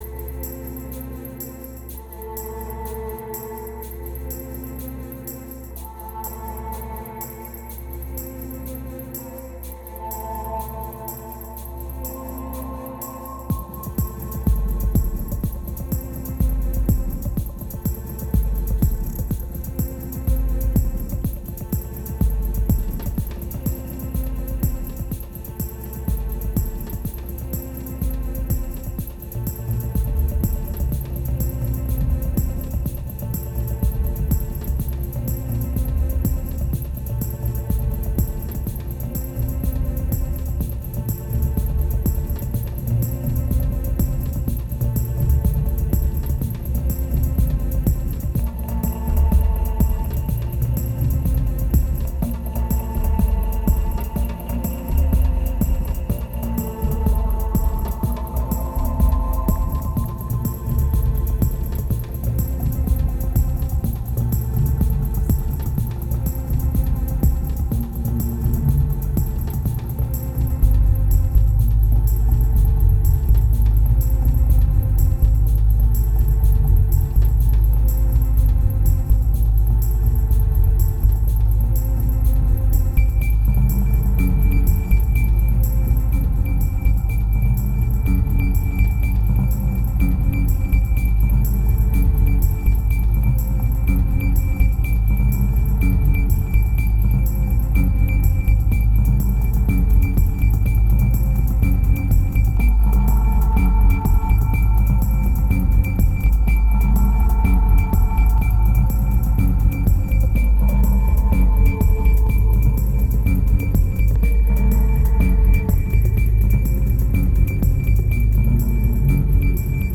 winter moods recorded in Paris
2076📈 - -60%🤔 - 124BPM🔊 - 2010-12-05📅 - -335🌟